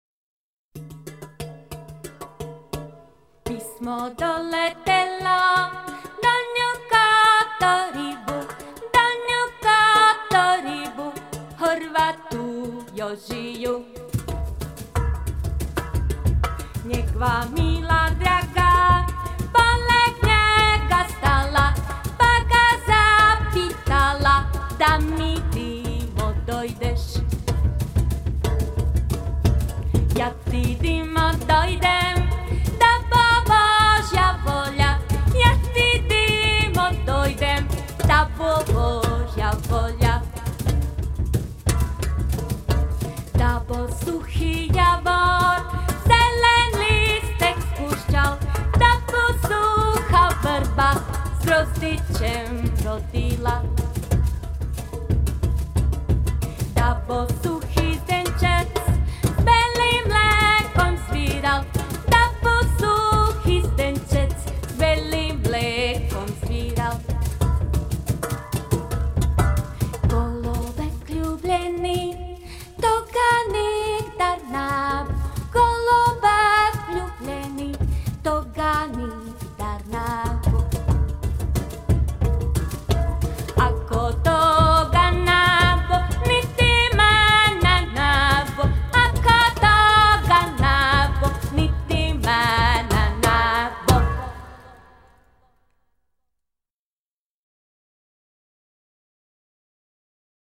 glas i gitara
glasovir i udaraljke
cimbale